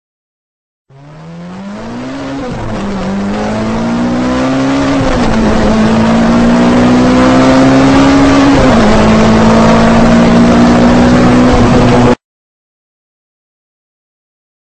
Auto Acelerando Carrera   Efectos de Sonido.mp3